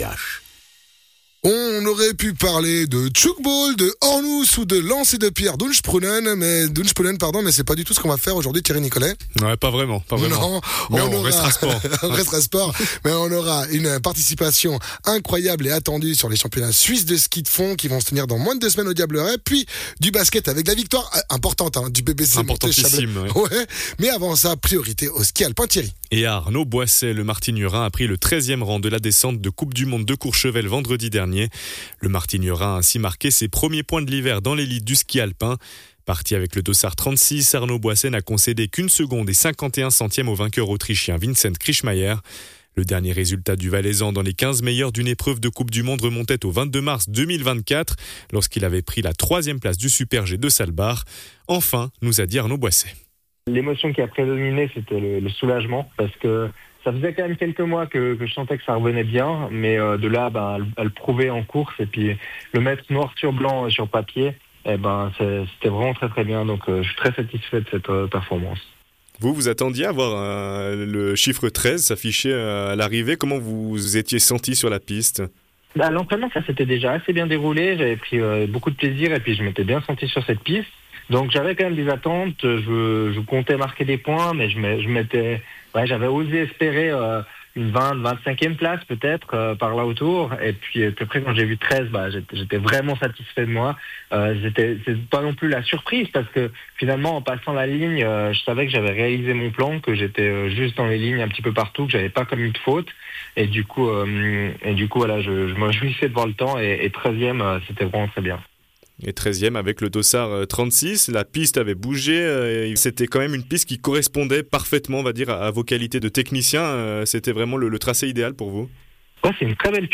Intervenant(e) : Arnaud Boisset, skieur professionnel